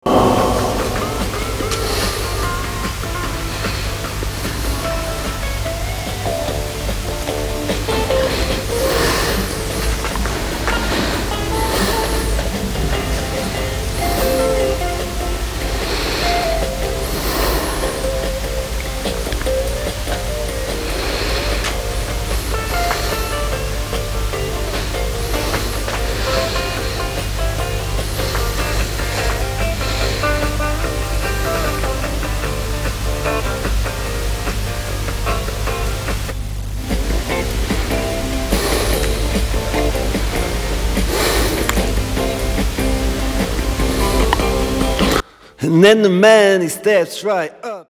And that’s the neck pick-up, I’d think the FS-1, isn’t it?